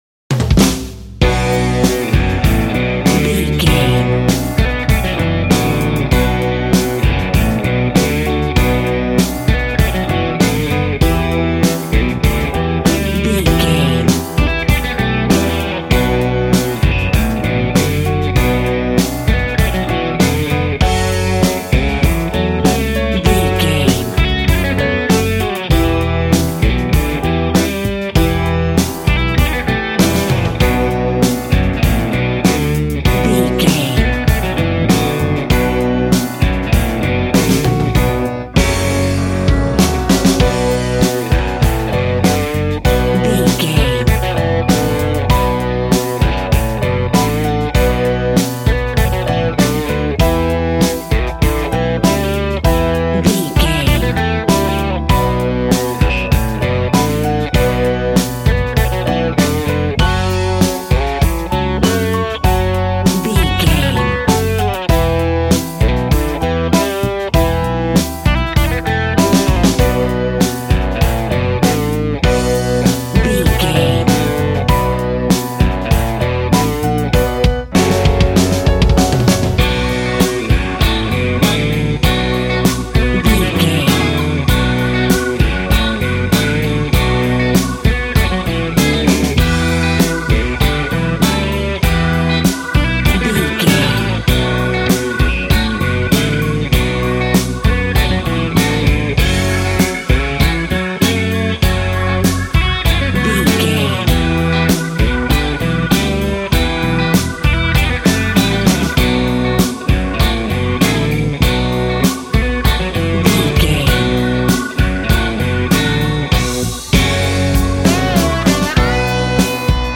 Aeolian/Minor
sad
mournful
hard
bass guitar
electric guitar
electric organ
drums